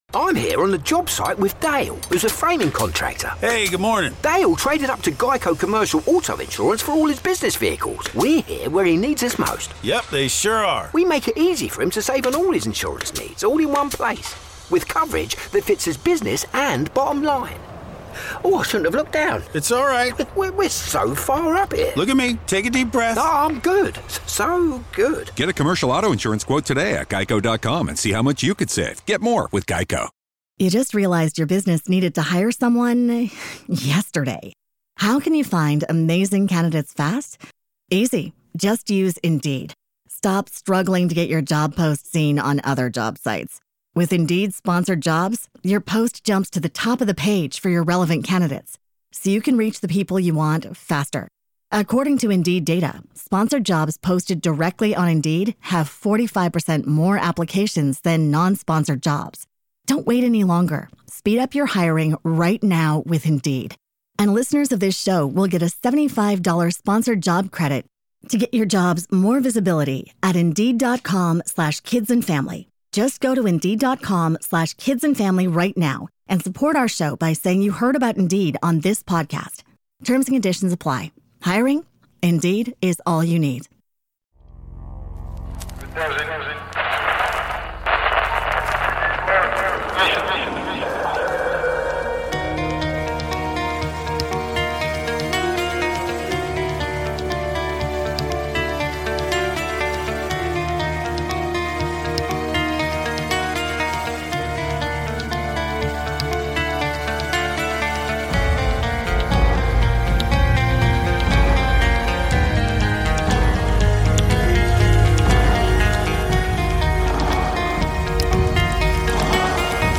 Witnesses of bigfoot, sasquatch, ufo's, aliens, ghosts and an array of other creatures from the paranormal and cryptozoology realm detail their encounters.